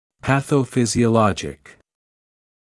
[ˌpæθəuˌfɪzɪə’lɔʤɪk][ˌпэсоуˌфизиэ’лоджик]патофизиологический